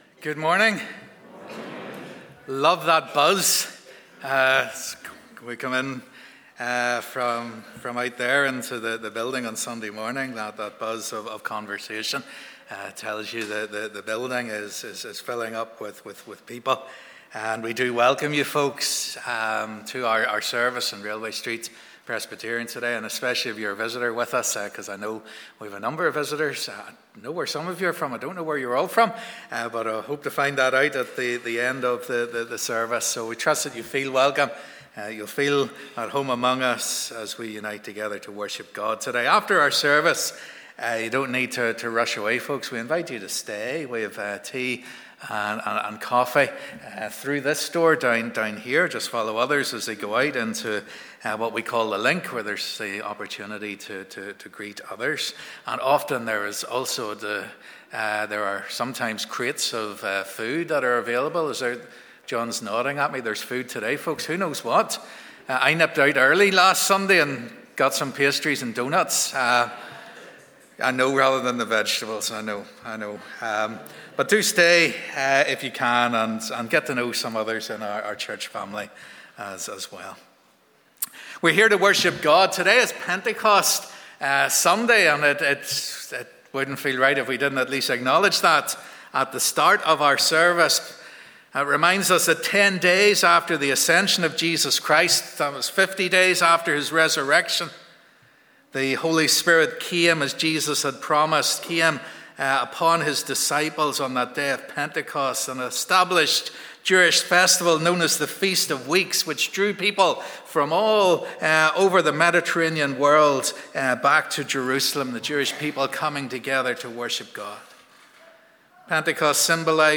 Live @ 10:30am Morning Service